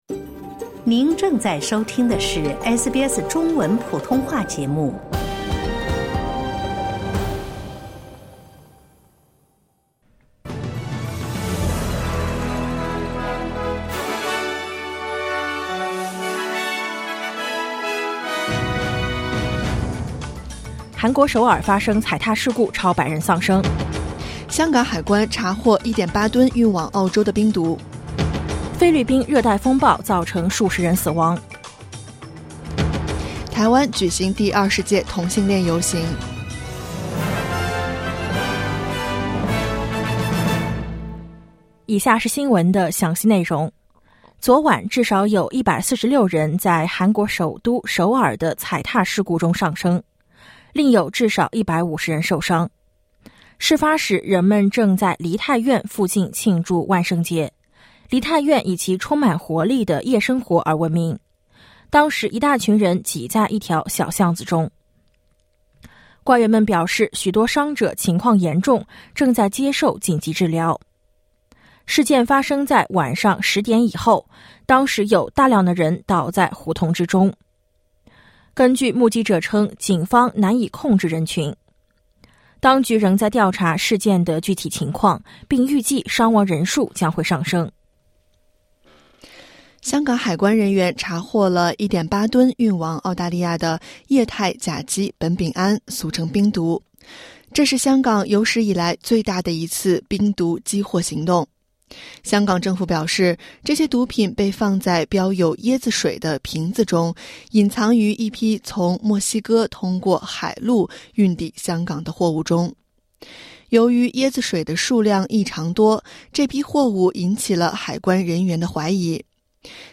SBS早新闻（2022年10月30日）